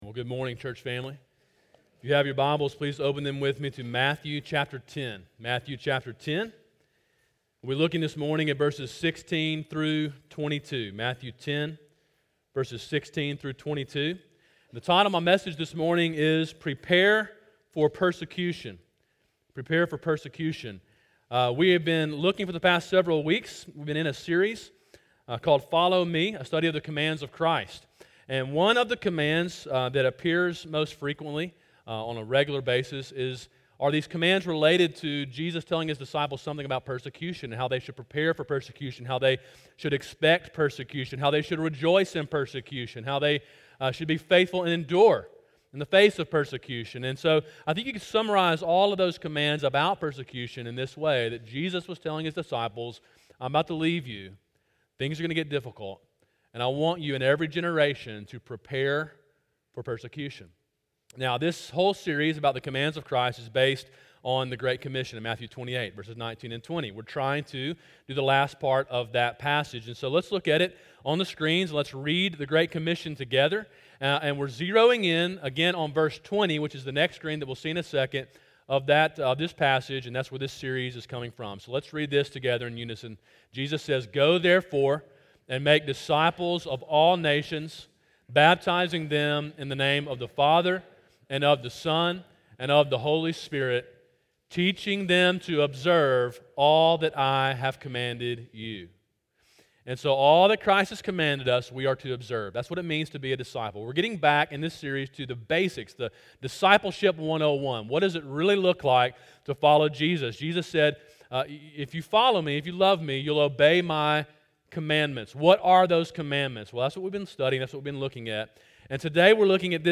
Sermon: “Prepare for Persecution” (Matthew 10:16-22) – Calvary Baptist Church